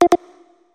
notification_sounds
dutdut.mp3